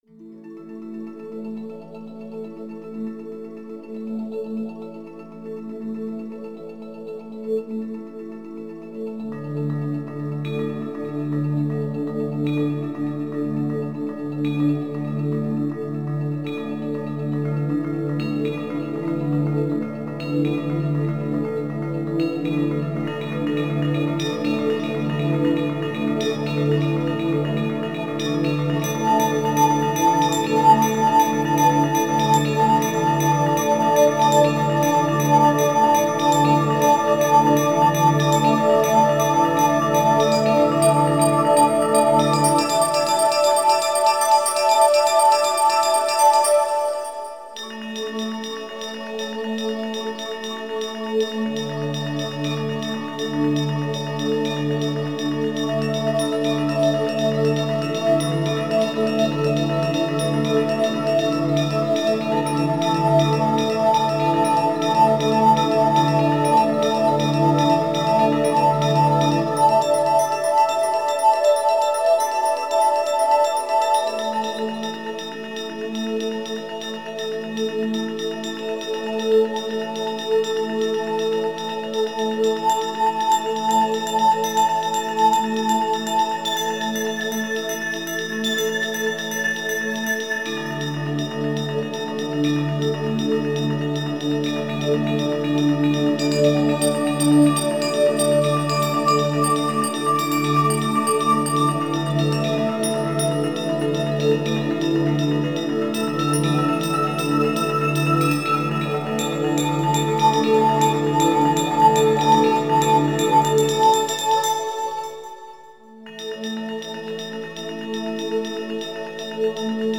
Духовная музыка Медитативная музыка Мистическая музыка